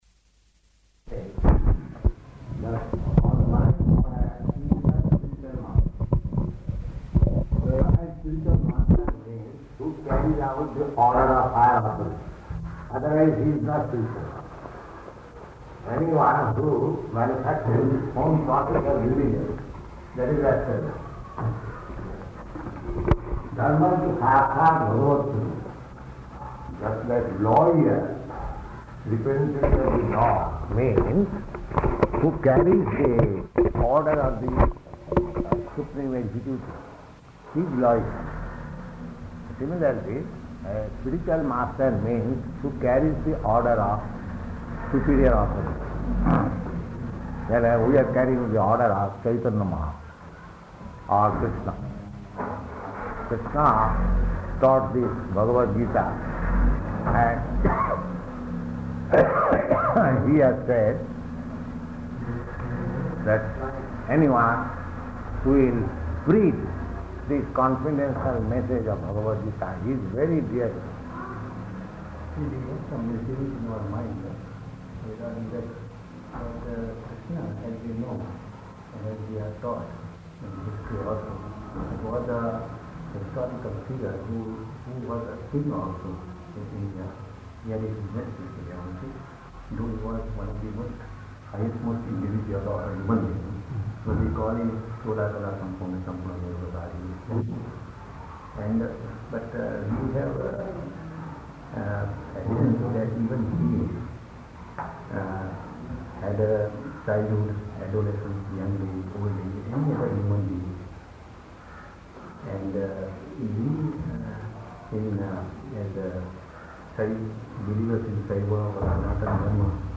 Room Conversation
Room Conversation --:-- --:-- Type: Conversation Dated: January 18th 1971 Location: Allahabad Audio file: 710118R1-ALLAHABAD.mp3 Prabhupāda: ...a bona fide [indistinct] spiritual master.